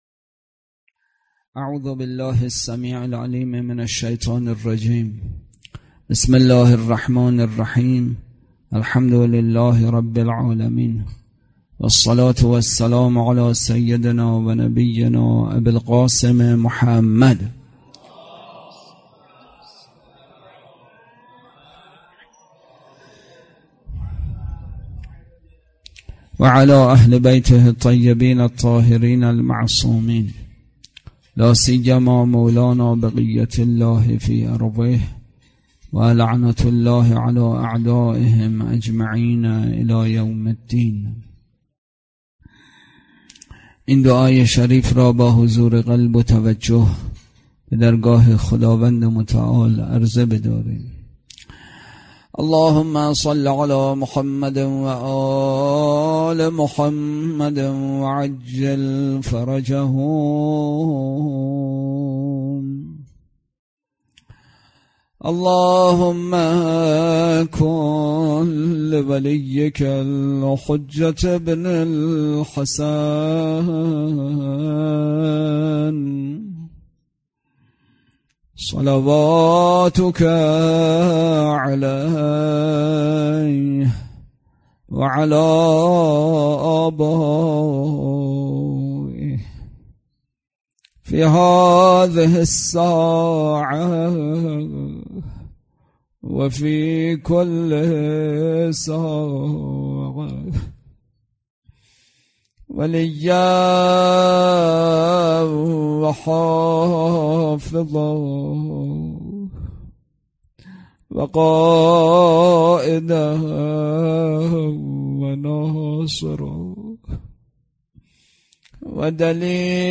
اطلاعات آلبوم سخنرانی
برگزارکننده: مسجد اعظم قلهک